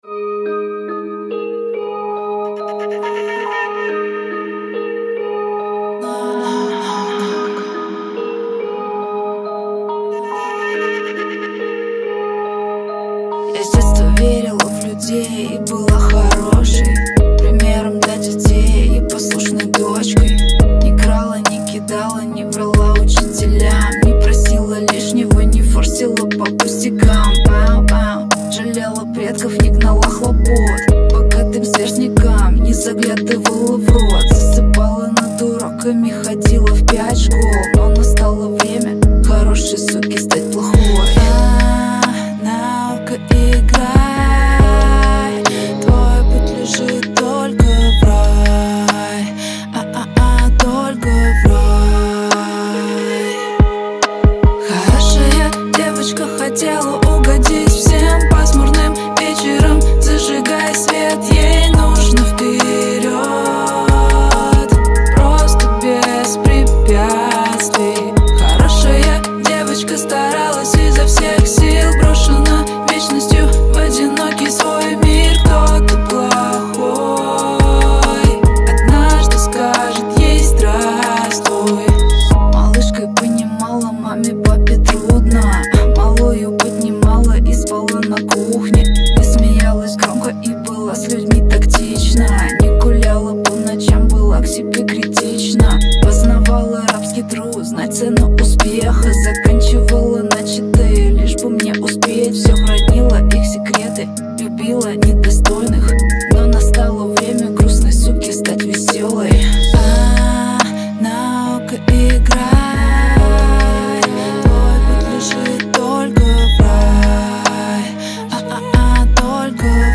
Релиз моего трека в жанре женский рэпчик.